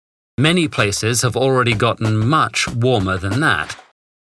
But examples aren’t hard to find, from young and not so young speakers:
But as we heard in the clips above, it’s possible to find Brits using gotten with a wide variety of meanings.